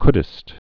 (kdĭst) or couldst (kdst)